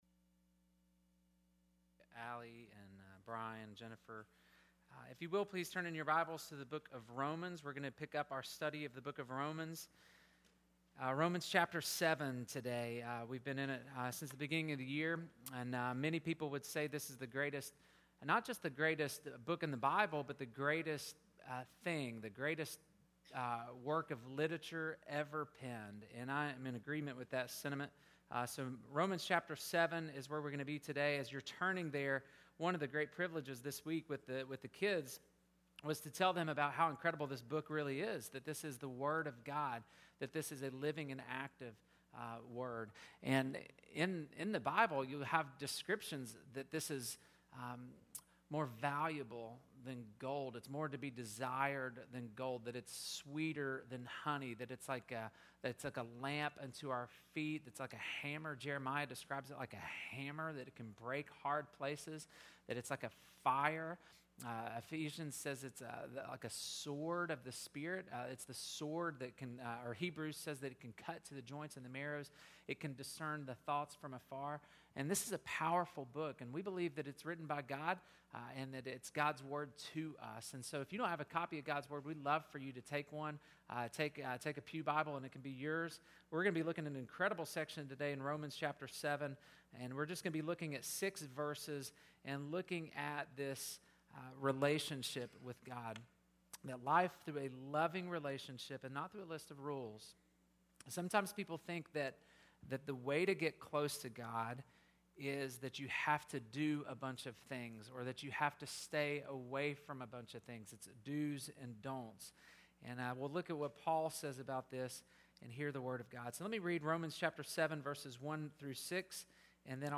Life Through a Loving Relationship, Not a List of Rules July 21, 2019 Listen to sermon 1.